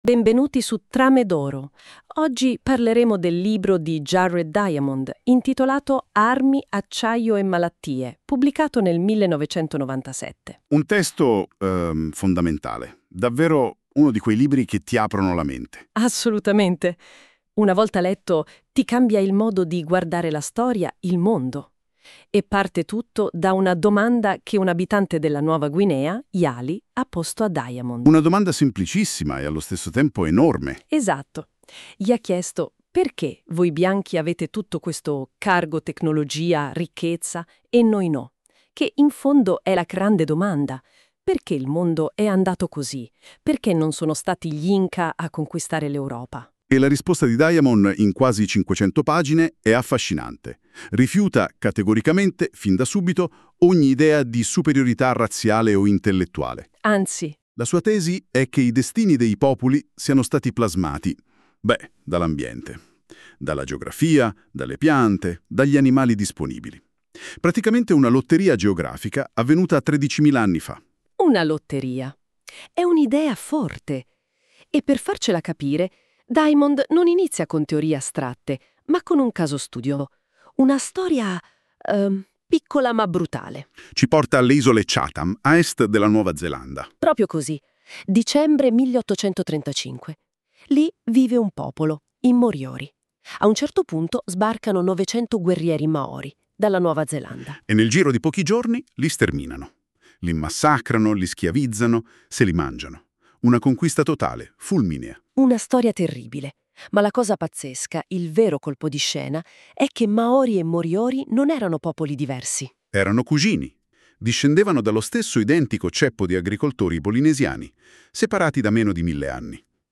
Discussione a due voci sul libro